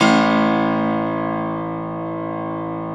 53e-pno01-A-1.wav